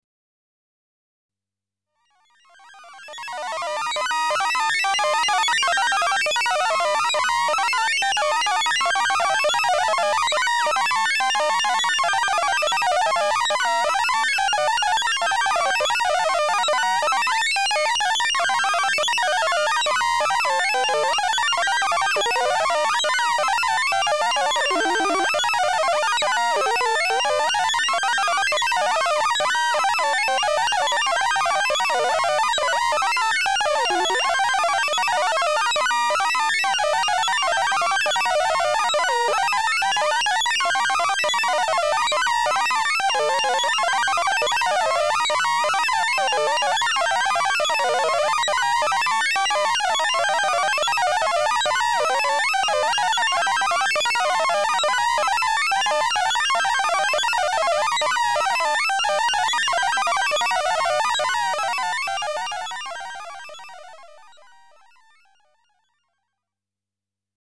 Компьютерное щебетание (версия 2)
kompyuternoe_shebetanie_versiya_2_7lk.mp3